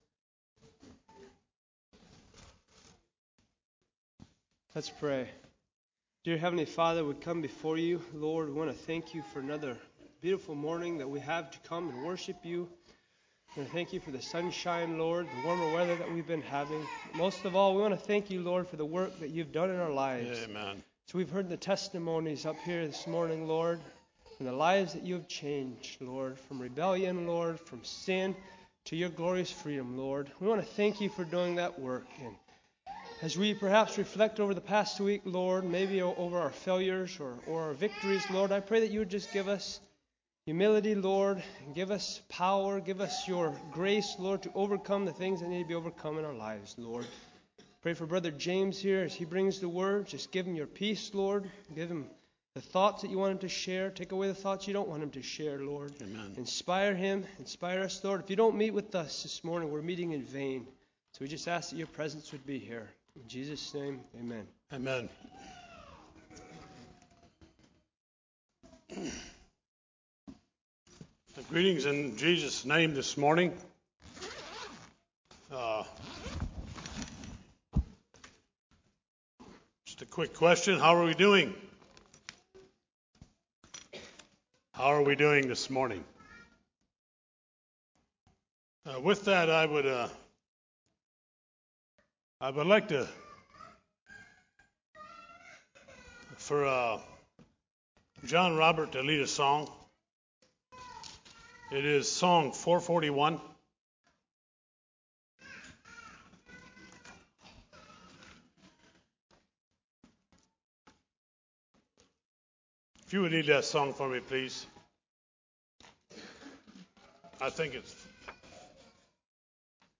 ACCF Sermons